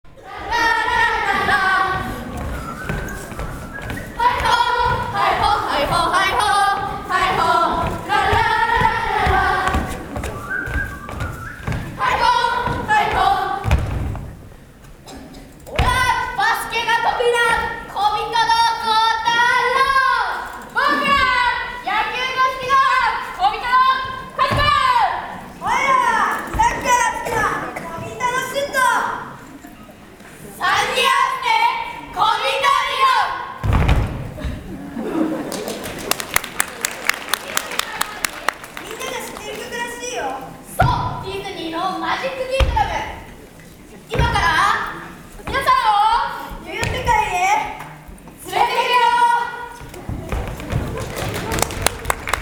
２月２５日（土）に本校体育館でスポーツフェスタが開催されました。
オープニングでは吹奏楽部が演奏して盛り上げました。